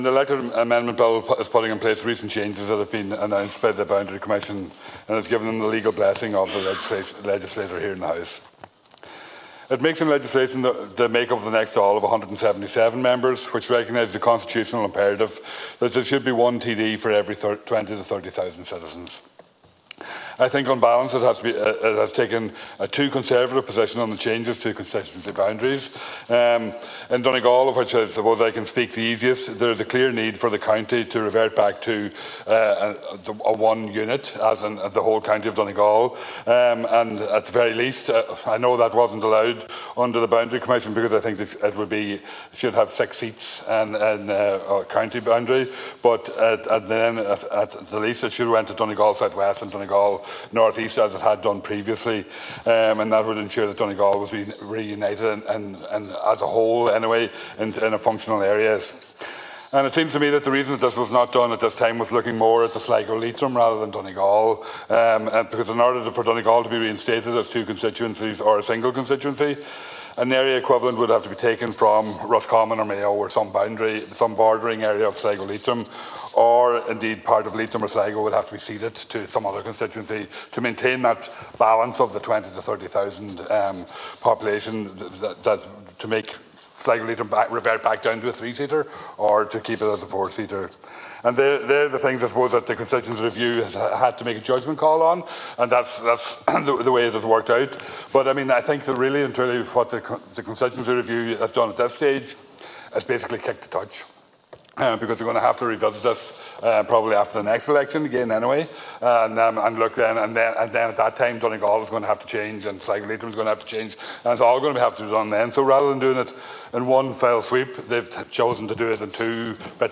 Speaking during a Dail debate on the second stage of the Electoral Bill last evening Deputy Pringle said there was a widespread expectation that changes would be made this year, and he believes the reason it didn’t happen was in order to boost the figures in Sligo Leitrim.